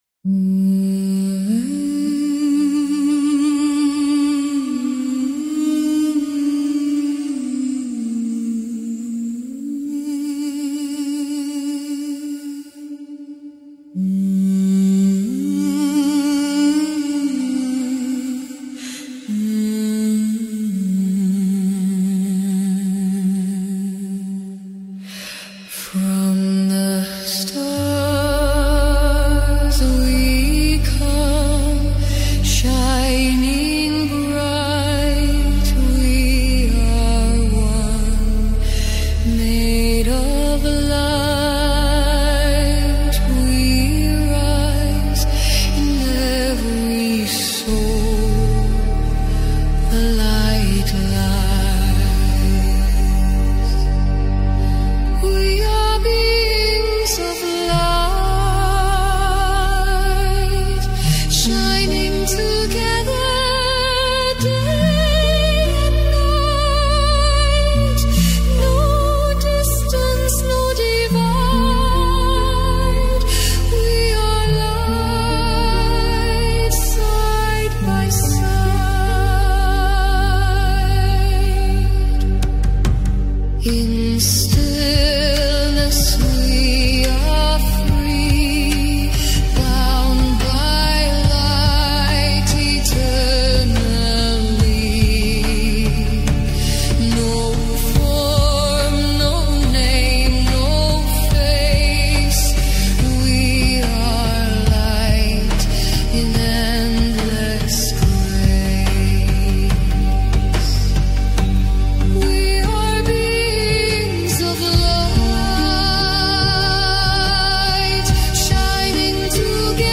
✨ A pedido de ustedes, el video COMPLETO de TODOS SOMOS SERES DE LUZ | MÚSICA PLEYADIANA ya está aquí. Sumérjanse en estas frecuencias elevadas que los conectarán con su esencia divina y les recordarán su verdadero origen.